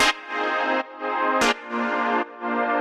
GnS_Pad-MiscB1:2_170-C.wav